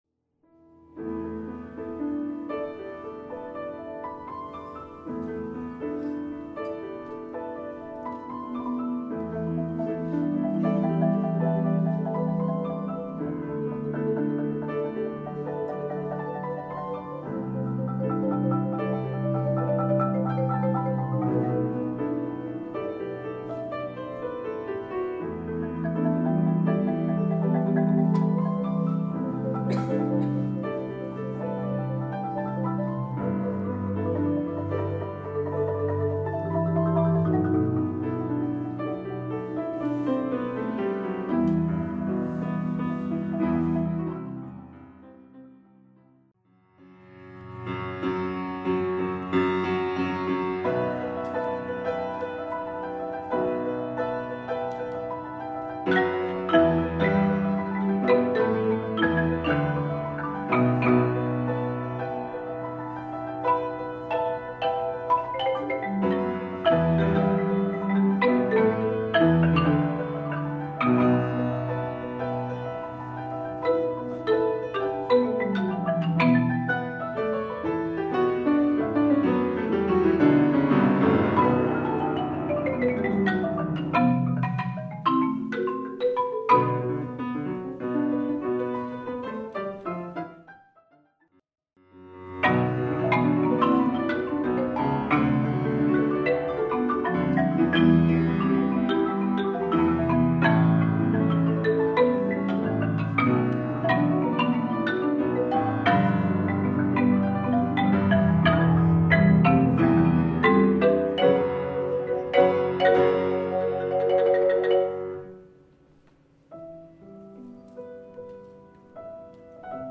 Genre: Duet for Marimba & Piano
Marimba (5-octave)
Piano